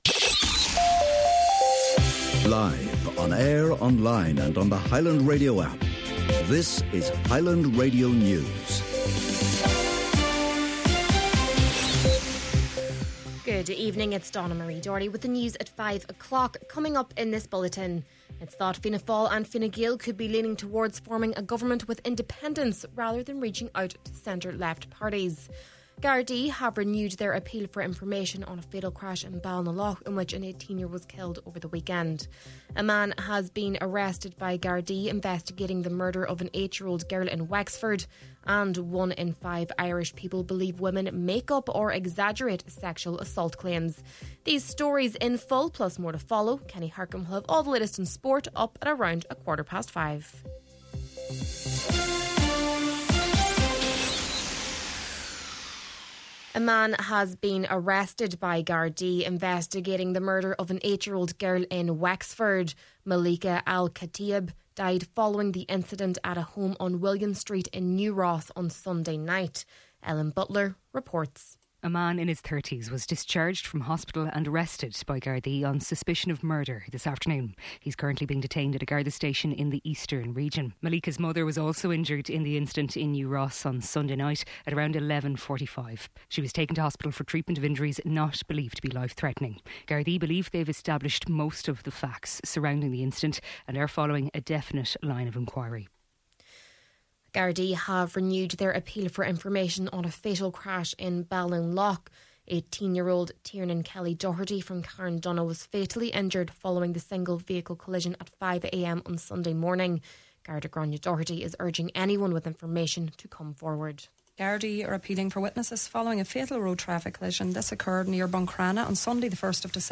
Main Evening News, Sport and Obituaries – Tuesday, December 3rd